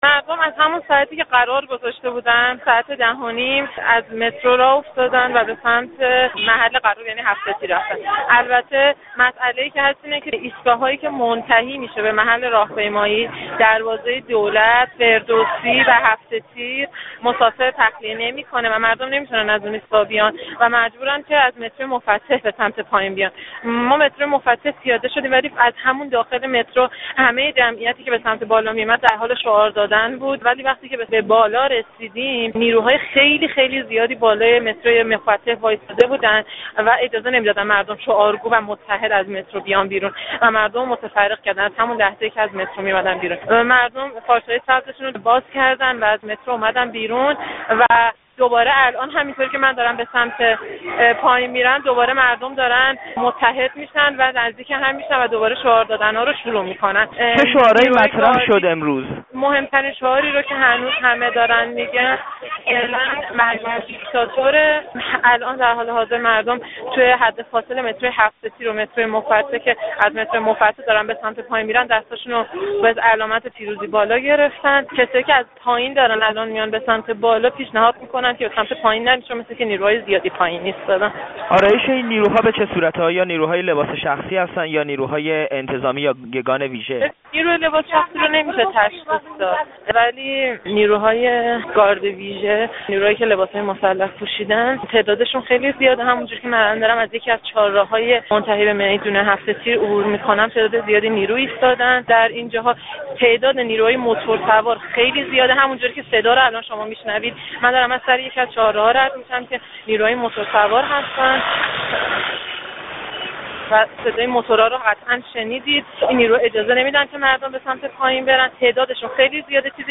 گفت‌وگو با یک شاهد عینی در مورد حال و هوای امروز تهران